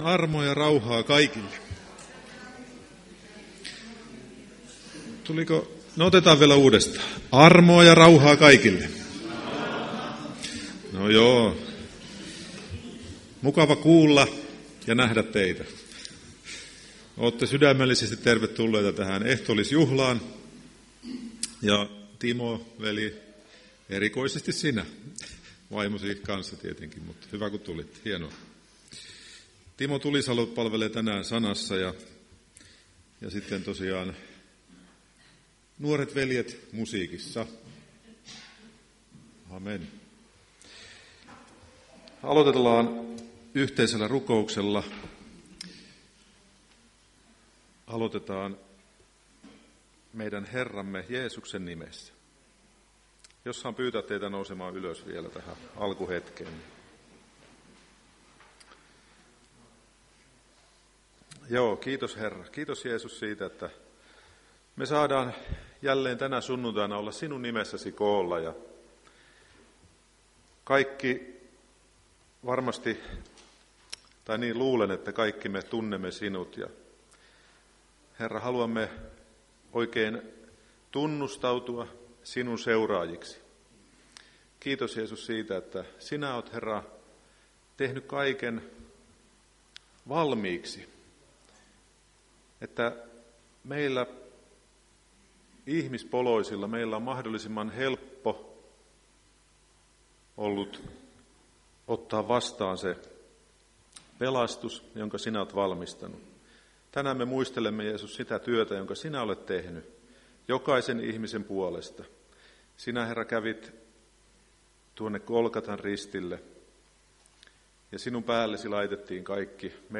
Ehtoolliskokous 2.3.2025